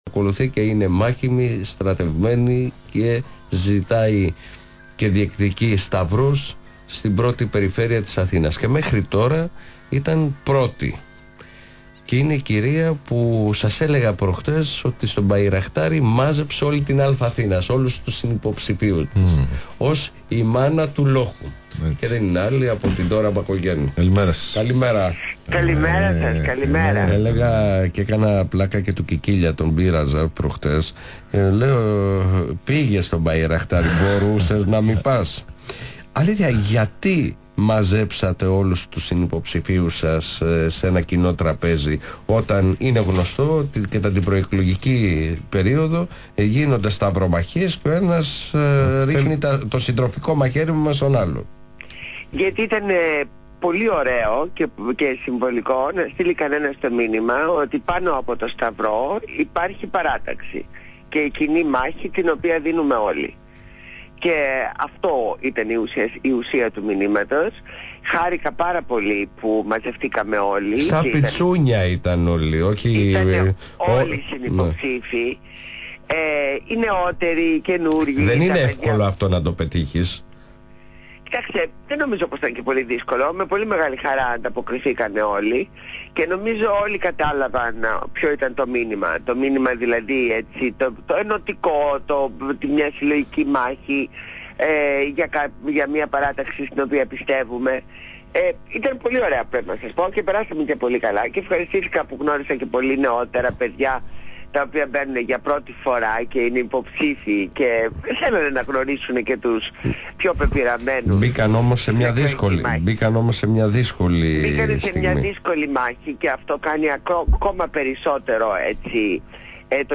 Συνέντευξη Ν. Μπακογιάννη στο ραδιόφωνο του ALPHA